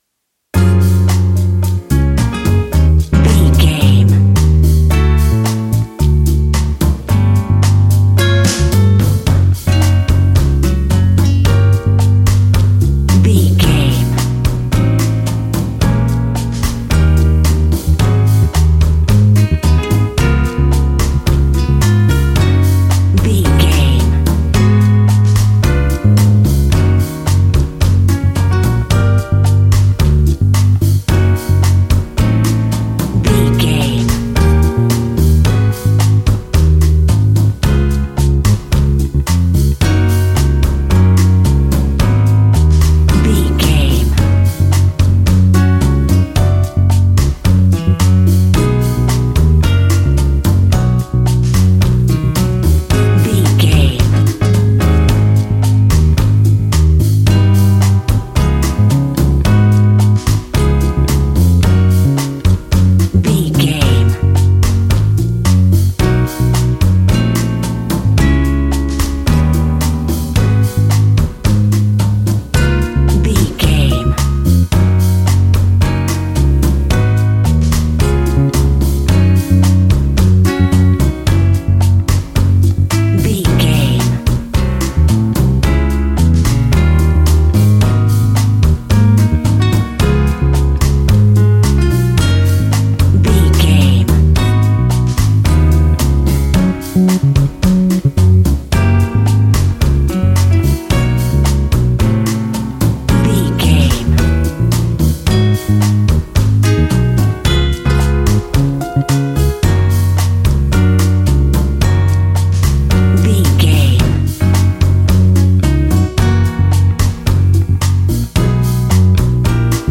An exotic and colorful piece of Espanic and Latin music.
Ionian/Major
F#
maracas
percussion spanish guitar